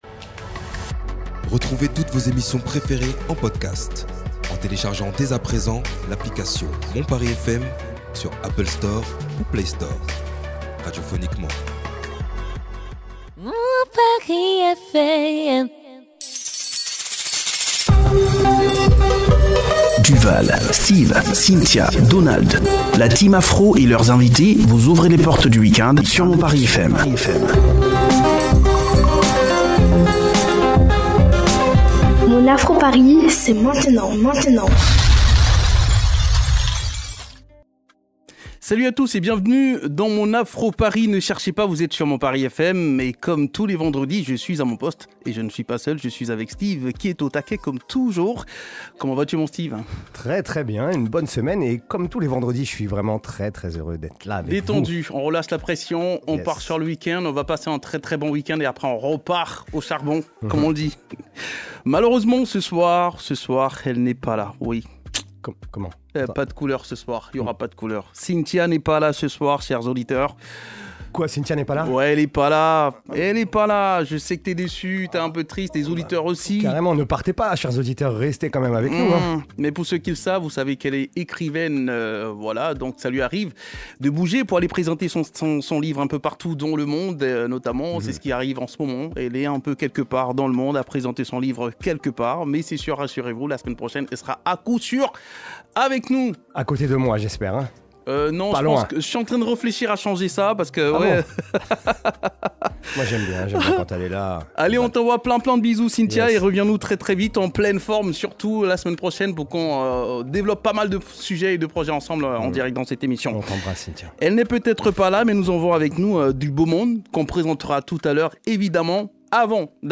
Le talkshow africain reçoit des promoteurs de festivals :1-